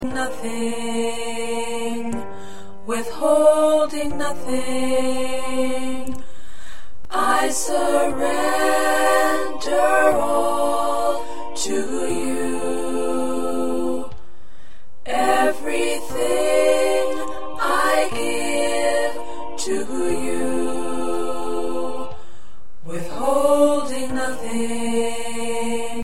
4 parts — Soprano, Alto, Tenor, and Lead